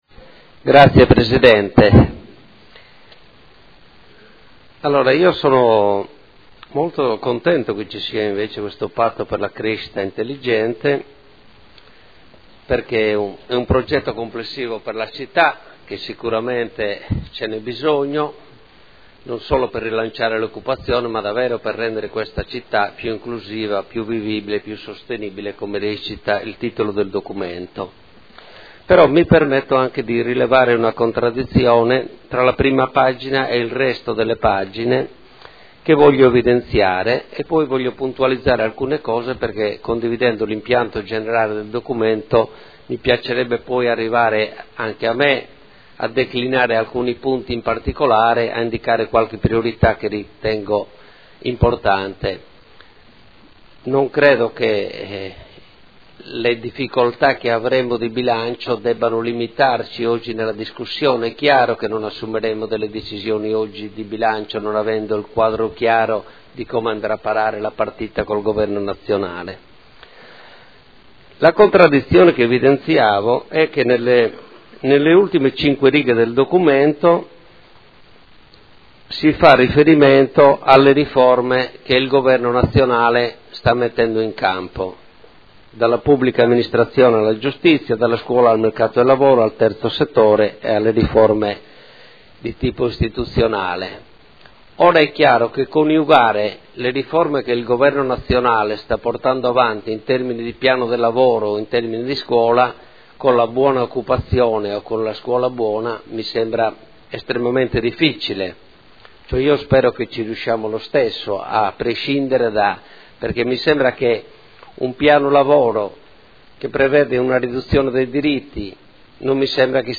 Seduta del 20/11/2014. Dibattito su Ordini del Giorno e Mozione aventi per oggetto "Patto per Modena"